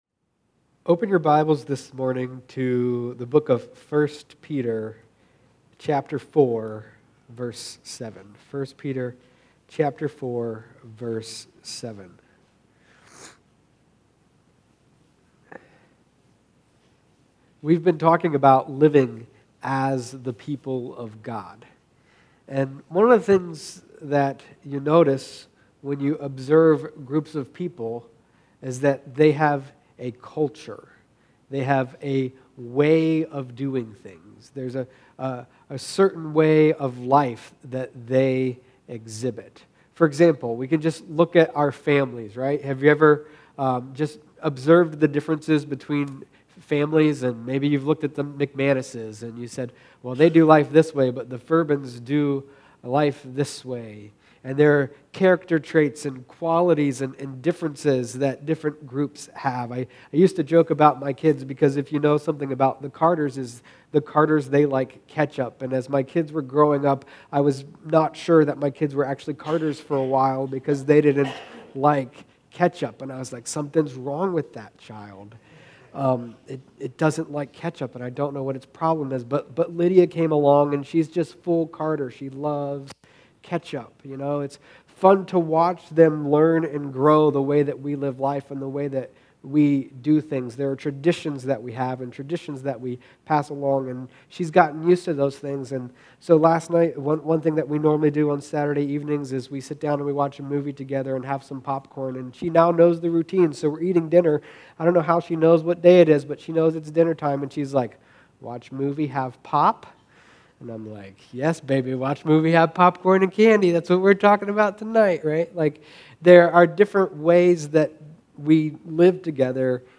Sermons | New Life Church